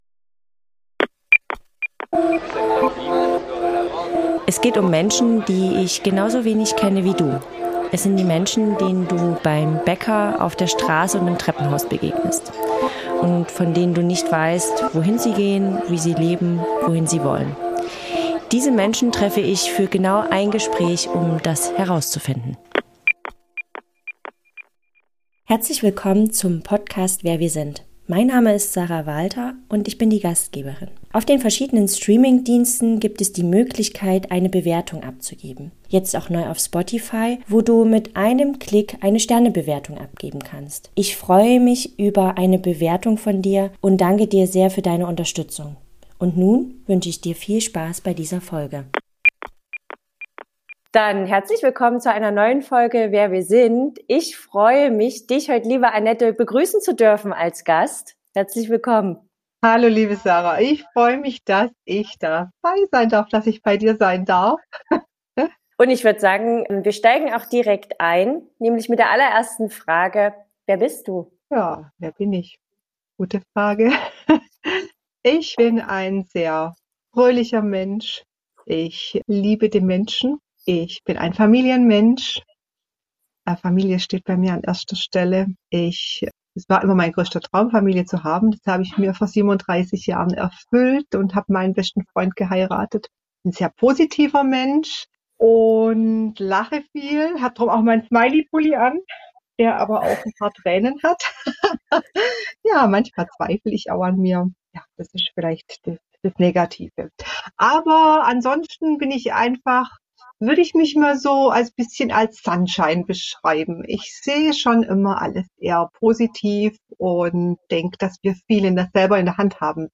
- ein Gespräch. ohne Skript. ohne Labels.